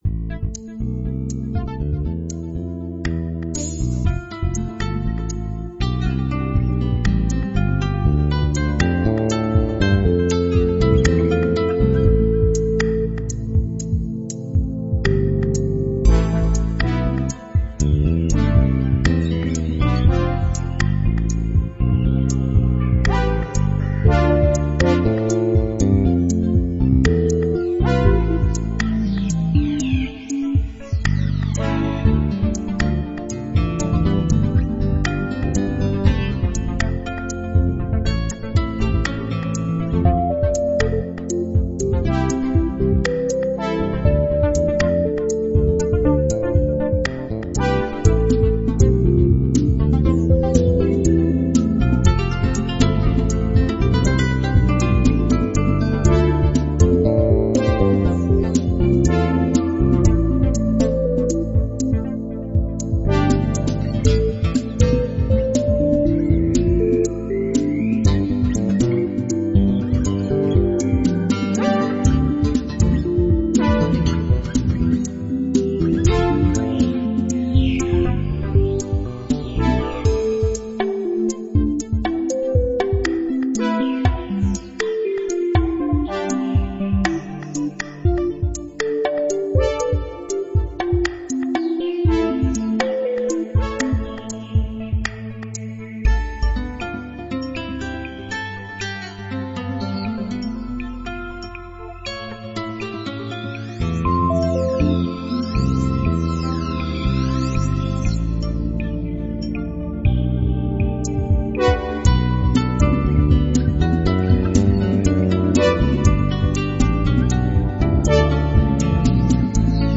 Relaxed Soft Electro Soundtrack music